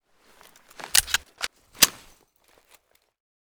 fn57_reload.ogg